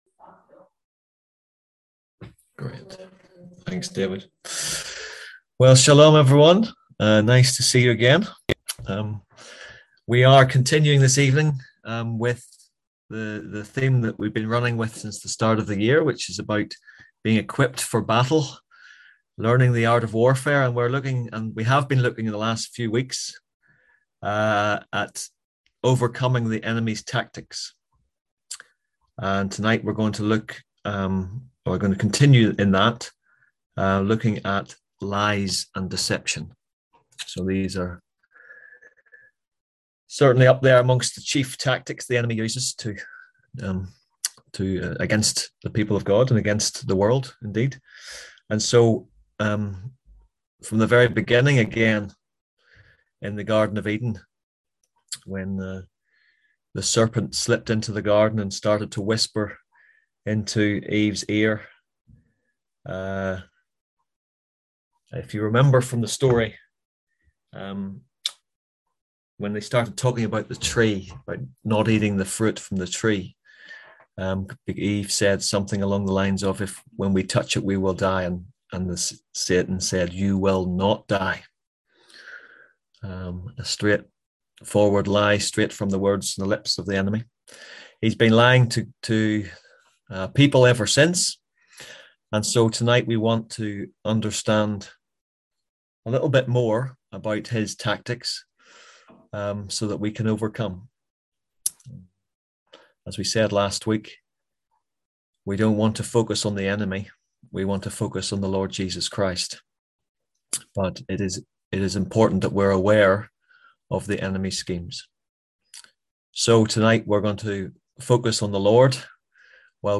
Click here to listen to the full story of our F14 conference, ‘when Christians disagree’.